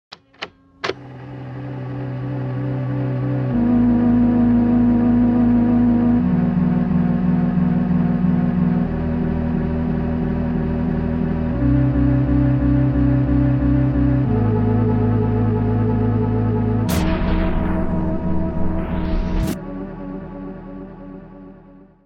Wifies death|sorry for bad quality